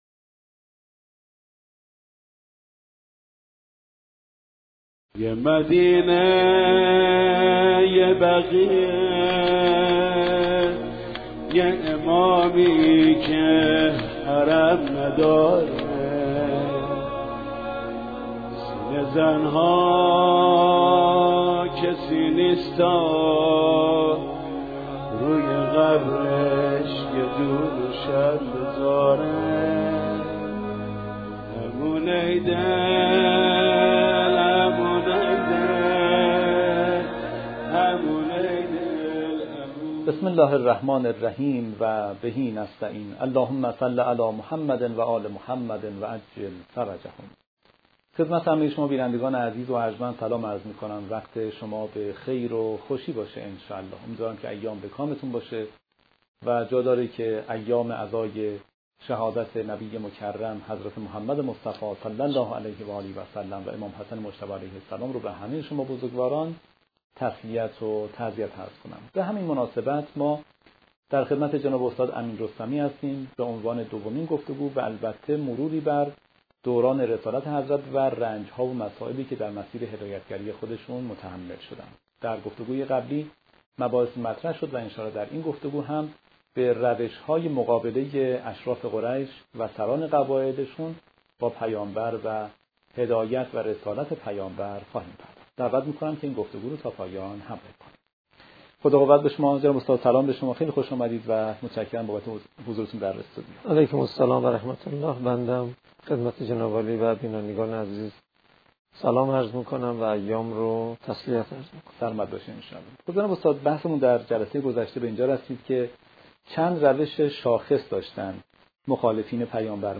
مقدمه و تسلیت این گفتگو به مناسبت ایام شهادت پیامبر اکرم(ص) و امام حسن مجتبی (ع) برگزار شده و به بررسی رنج‌ها و روش‌های مقابله مشرکان قریش با رسالت پیامبر (ص) می‌پردازد.